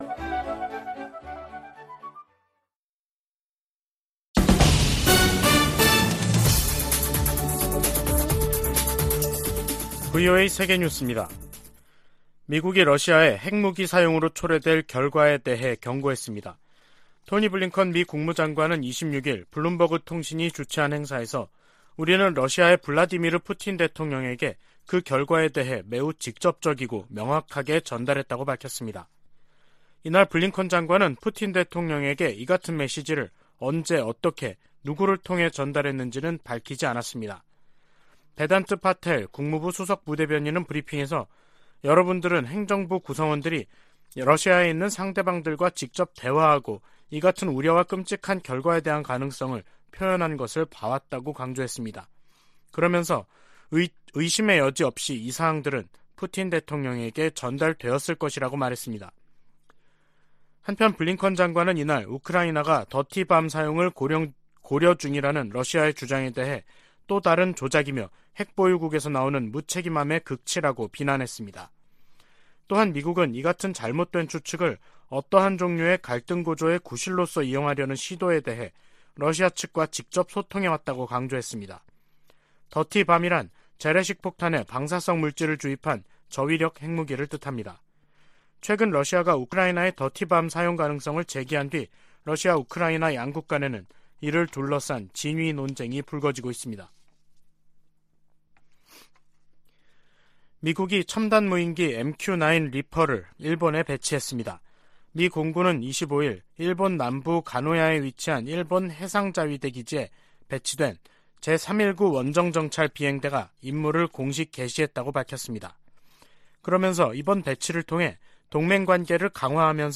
VOA 한국어 간판 뉴스 프로그램 '뉴스 투데이', 2022년 10월 26일 3부 방송입니다. 북한이 7차 핵실험을 감행할 경우 연합훈련과 추가 제재 등 다양한 대응 방안이 있다고 미 국무부가 밝혔습니다. 한국 국가정보원은 북한이 미국의 11월 중간선거 이전까지 7차 핵실험을 할 가능성이 있다는 기존의 정보분석을 거듭 제시했습니다. 2023 회계연도 국방수권법안에 대한 미국 상원 본회의 심의가 시작됐습니다.